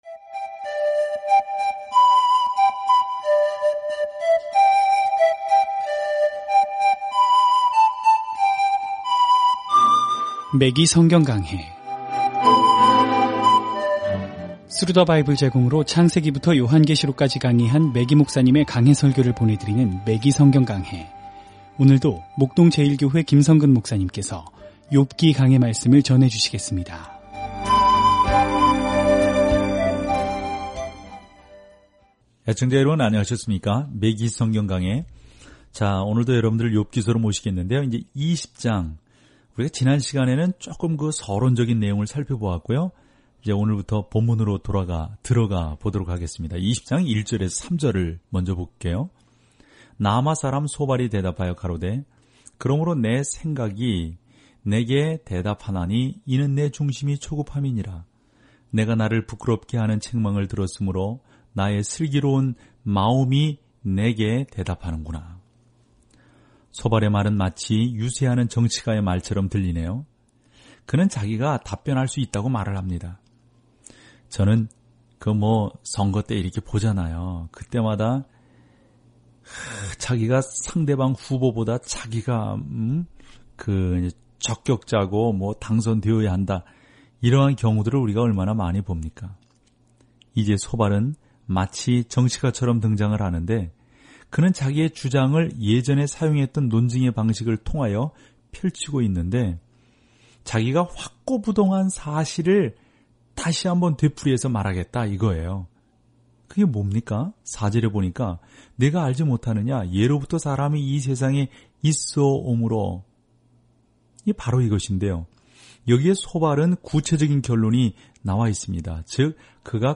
오디오 공부를 듣고 하나님의 말씀에서 선택한 구절을 읽으면서 매일 욥기를 여행하세요.